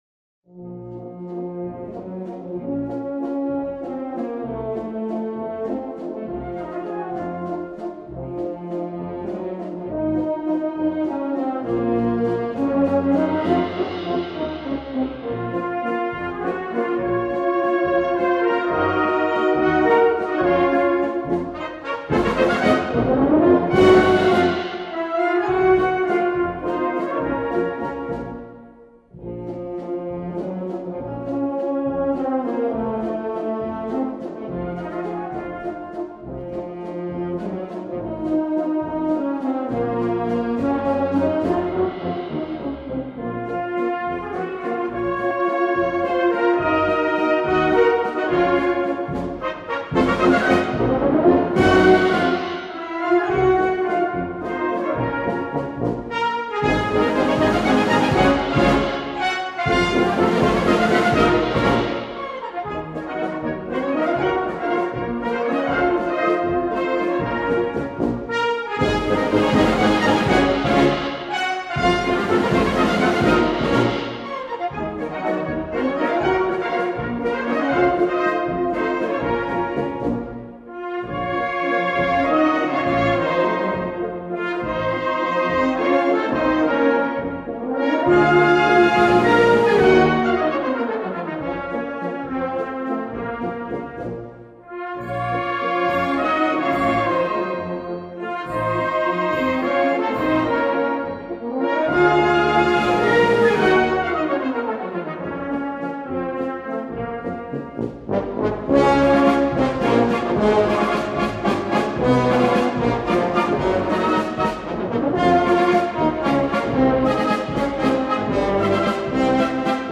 Предлагаю небольшой вечерний концерт старых вальсов в исполнении духовых оркестров.